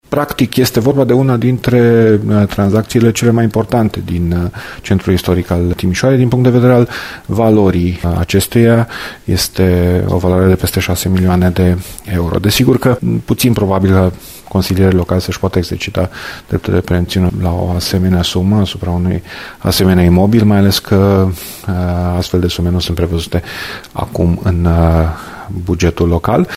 Consilierul local Dan Diaconu, fost viceprimar al Timișoarei, a explicat că tranzacția este una dintre cele mai mari din centrul istoric: